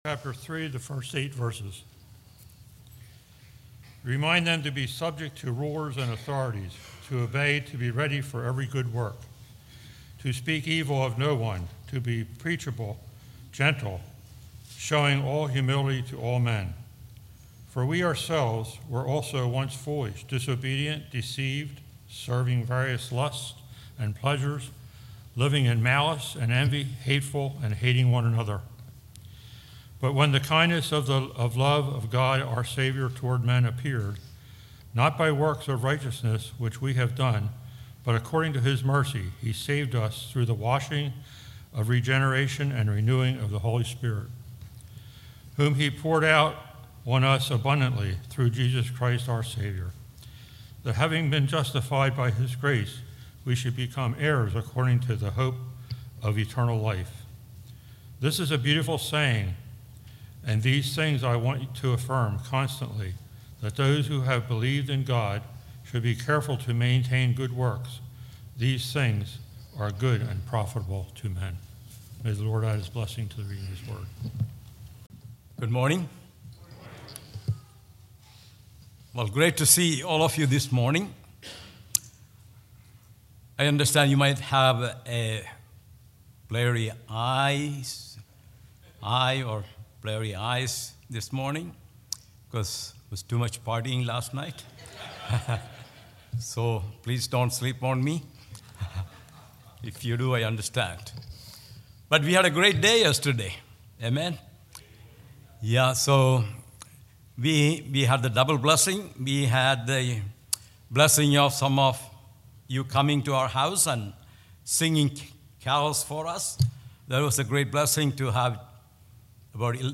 All Sermons Titus 3:1-8